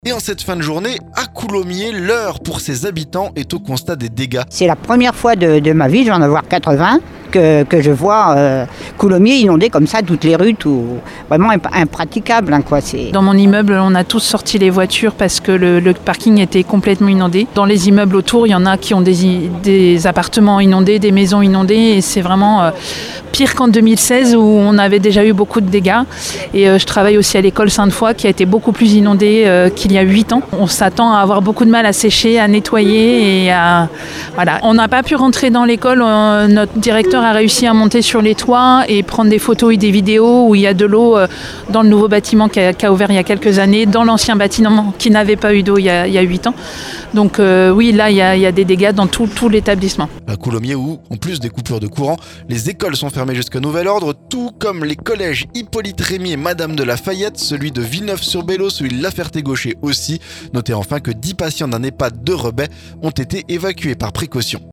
Reportage auprès des sinistrés de la crue du Grand Morin. Après Coulommiers et Crécy jeudi, le niveau augmente encore à Couilly-pont-aux-dames et Condé-sainte-libiaire ce vendredi.